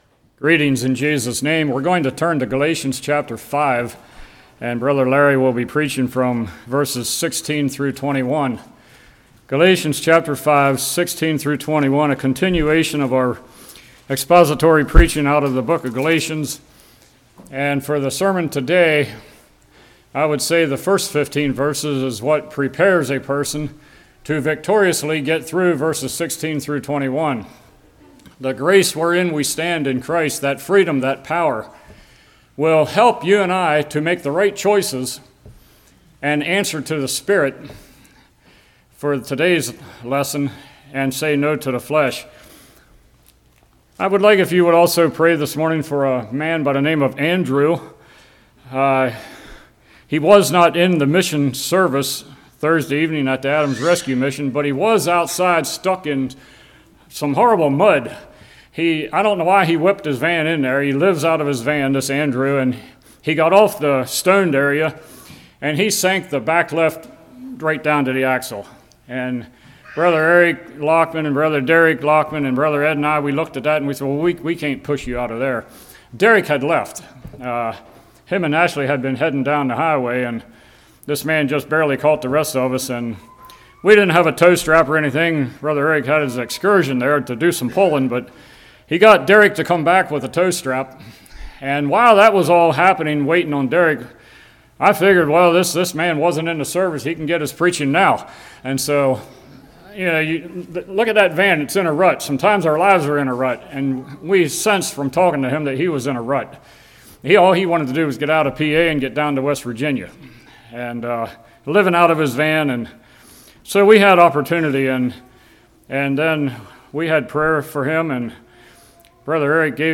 Galatians 5:16-21 Service Type: Morning How Do We Walk In The Spirit?